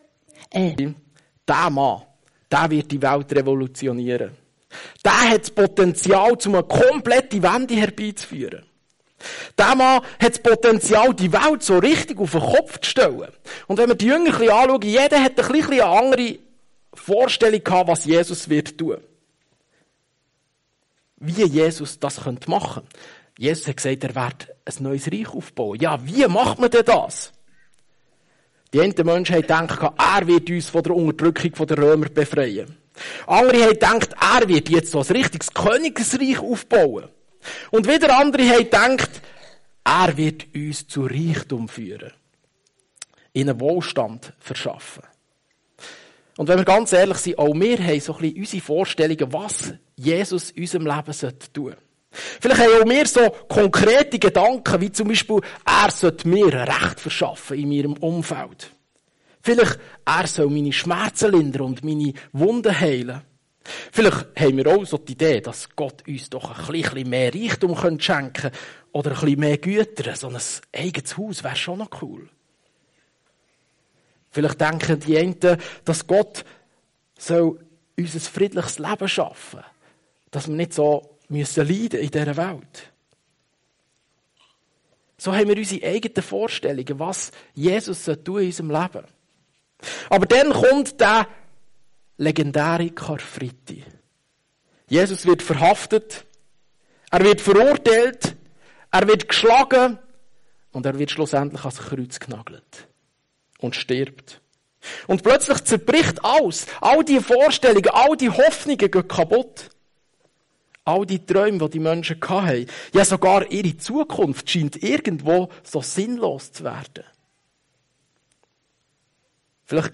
Familiengottesdinst - Open Topic ~ FEG Kloten Podcast